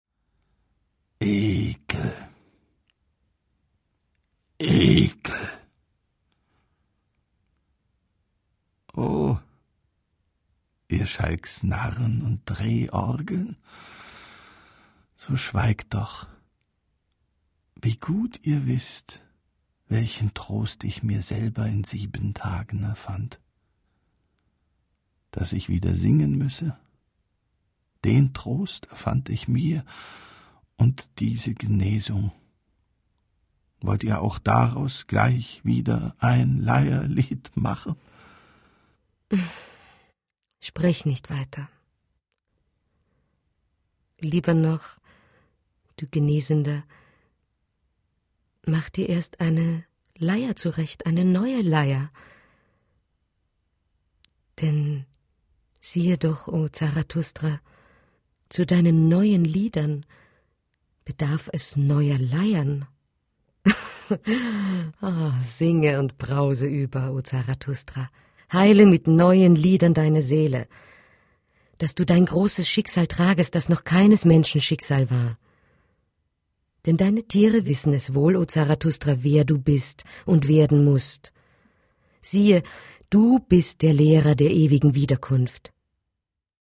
Deutscher Sprecher, Werbesprecher für Einkaufsparks und Center, Telfonjingles, Profischauspieler, Hörbücher, Hörspiele, Produzent, all inklusive, Overvoice, Voiceover, Dokfilm, Reportagen, Heimatfilm, Kommentar, Kurzfilm, Offsprecher, Synchron, Spielfilm, Dokumentation,
Sprechprobe: Sonstiges (Muttersprache):